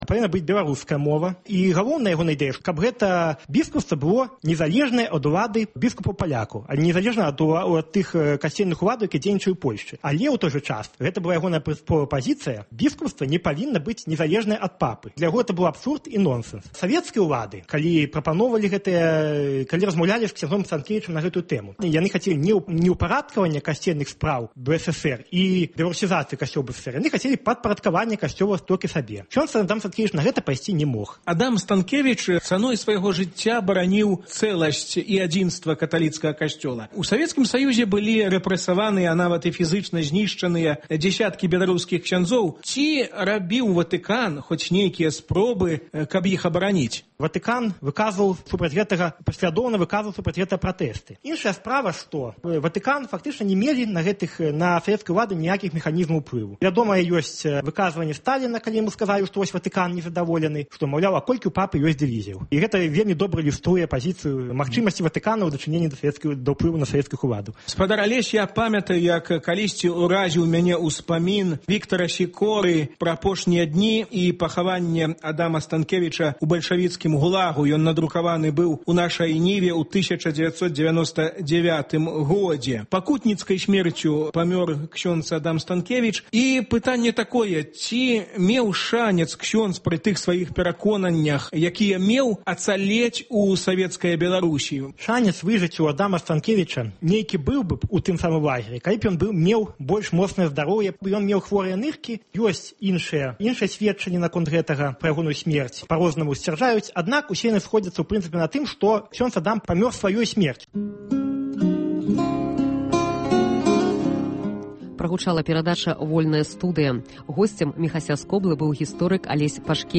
гутарыць з гісторыкам